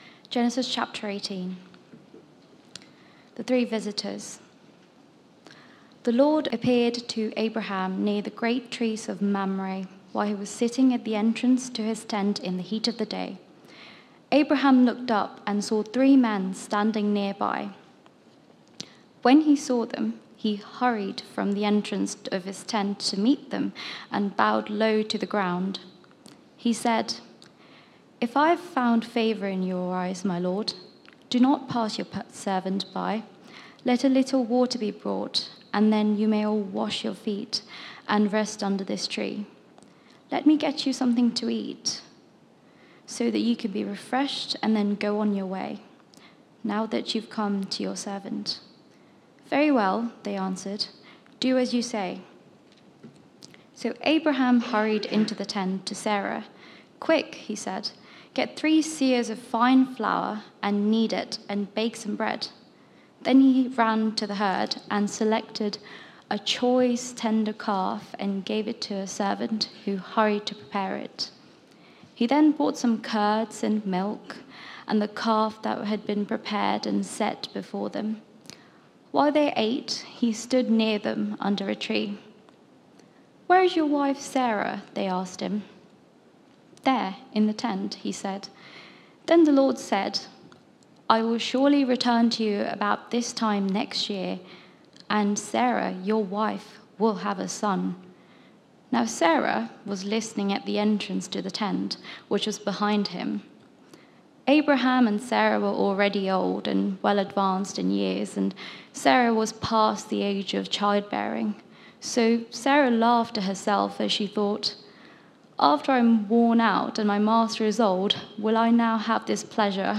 Media for Sunday Service on Sun 03rd Mar 2024 10:00
Theme: Sermon